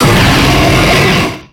Cri de Kyurem Noir dans Pokémon X et Y.
Cri_0646_Noir_XY.ogg